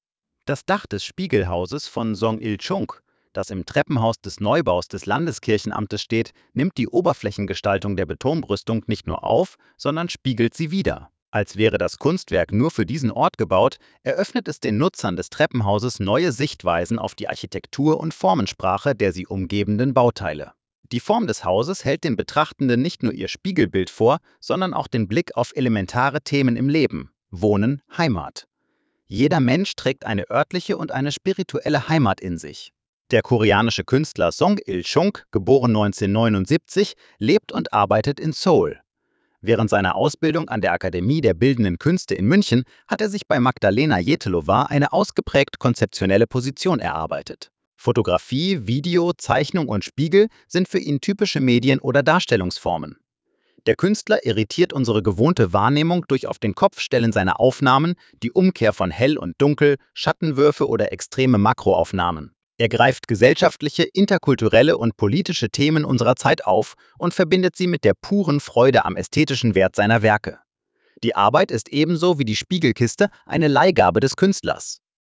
Audiostimme: KI generiert